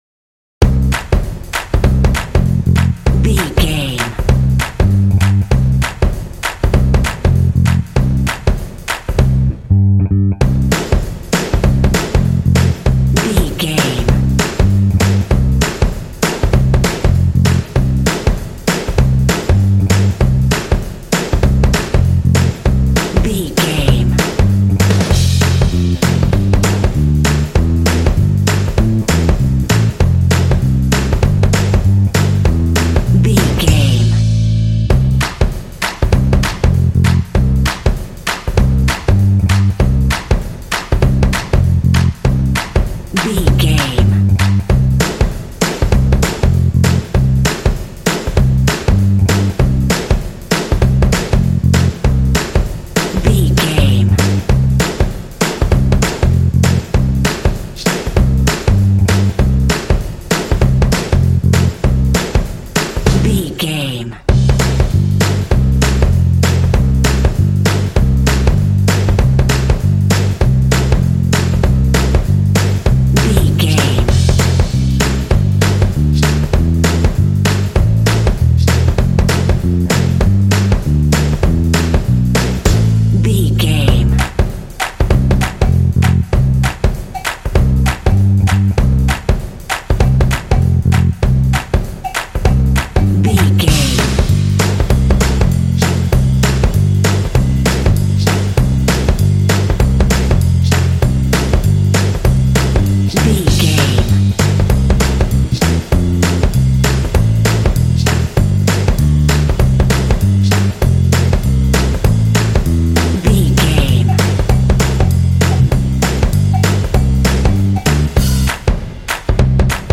Ionian/Major
bouncy
bright
driving
energetic
festive
joyful
bass guitar
drums
rock
contemporary underscore
alternative rock
indie